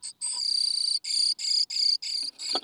ビブラートのかかった声を出す
メスが巣箱内で抱卵中、オスが餌を咥えて外に来たとき、メスがさかんに羽を震わしビブラートのかかった声を出す。